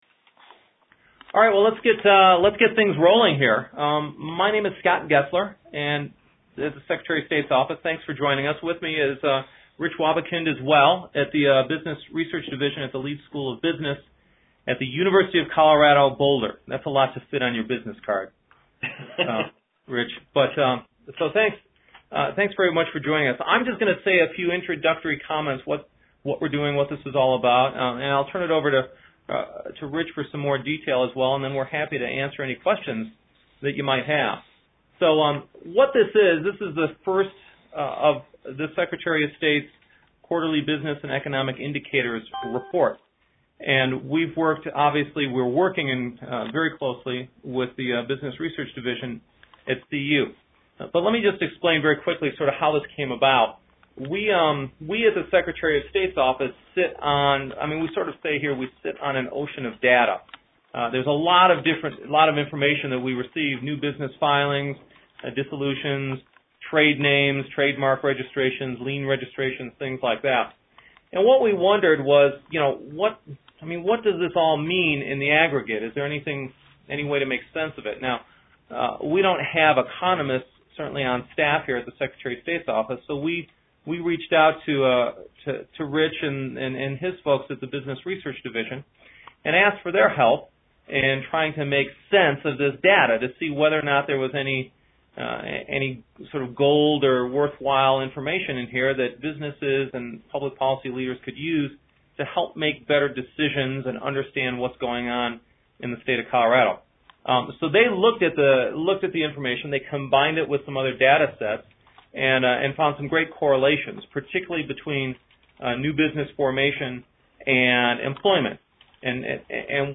2012 Q1 Report release conference call (MP3)